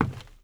Terrarum/assets/mods/basegame/audio/effects/steps/WOOD.3.wav at 8a1ff32fa7cf78fa41ef29e28a1e4e70a76294ea
WOOD.3.wav